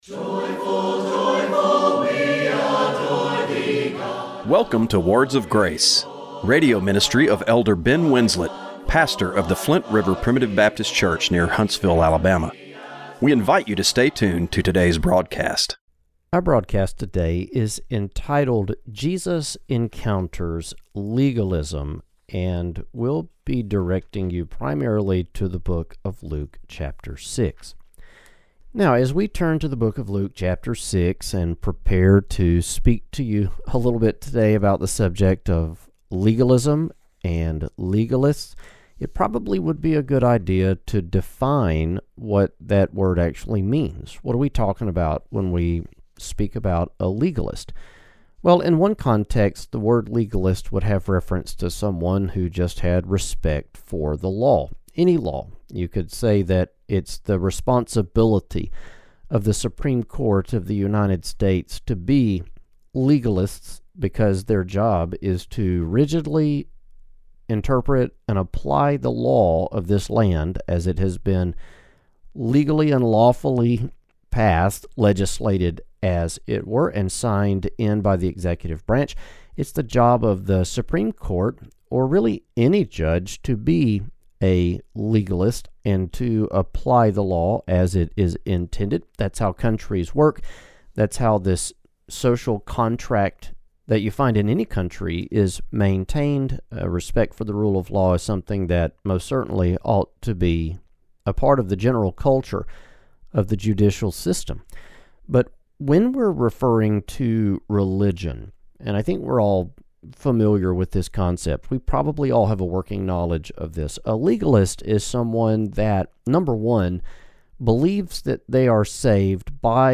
Radio broadcast for November 9, 2025.